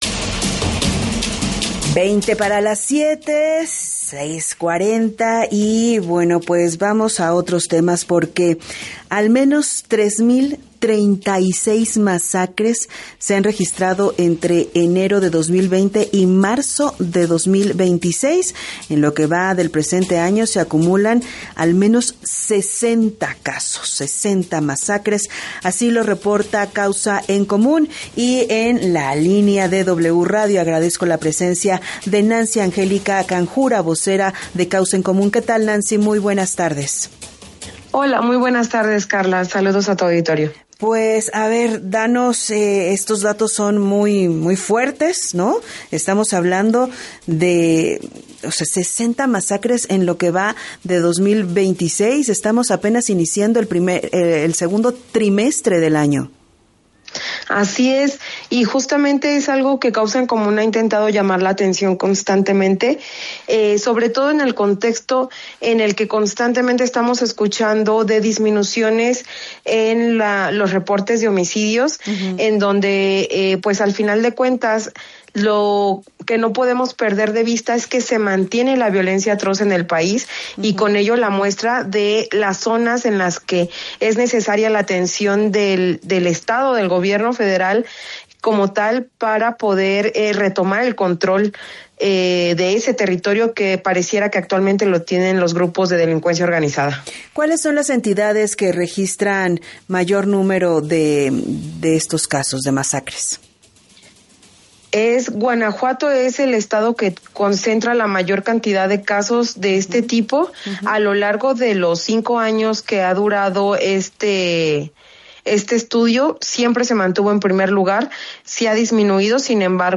La vocera advirtió sobre la existencia de “zonas de silencio” en estados como Zacatecas, donde la intimidación de los grupos criminales hacia la prensa local impide que muchos eventos violentos sean reportados, lo que sugiere que la cifra real podría ser aún mayor.